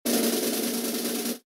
susSnare.wav